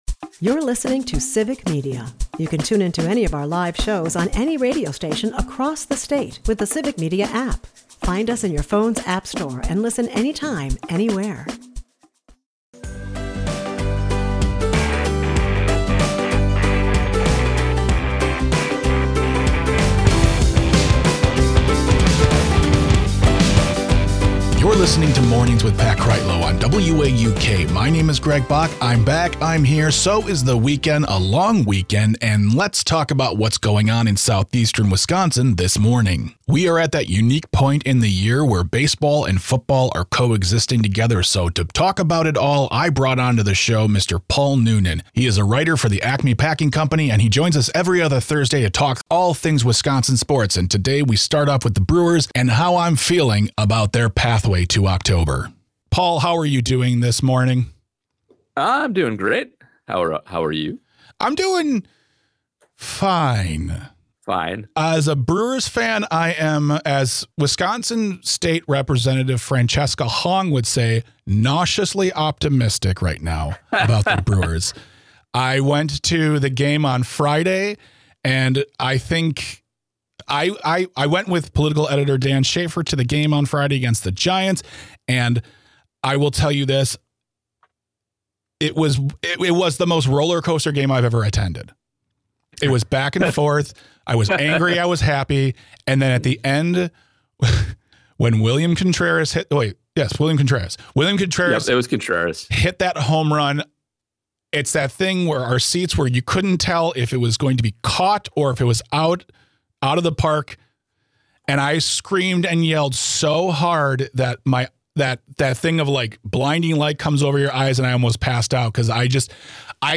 Today's show we are playing more of the interviews
WAUK Morning Report is a part of the Civic Media radio network and air four times a morning.